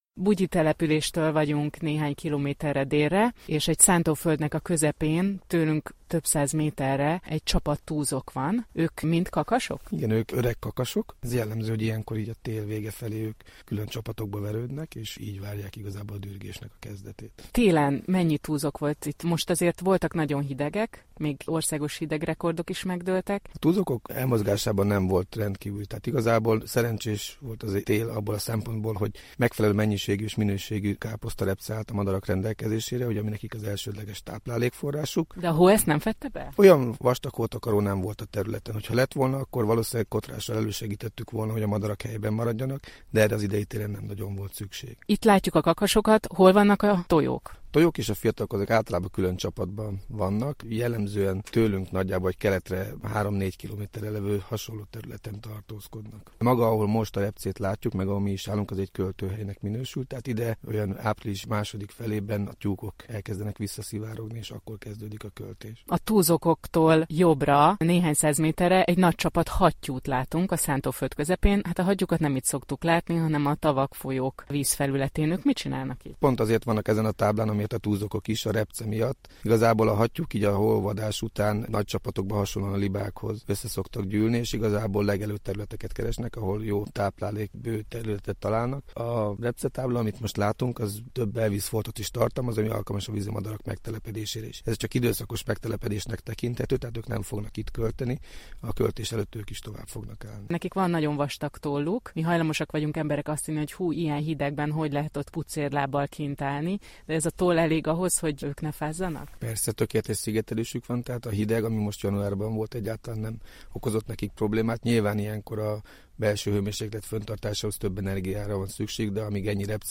Radio Post MR1 Kossuth from 20.09.2016